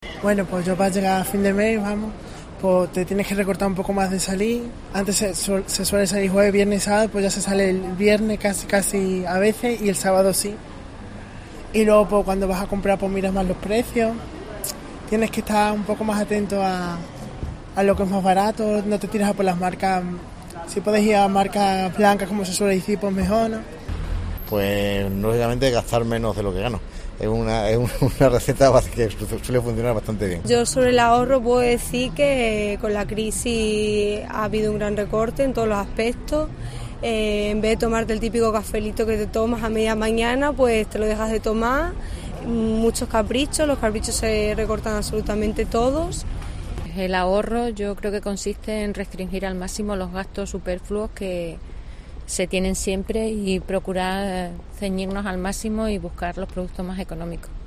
Los extremeños nos cuentan cómo llegan a fin de mes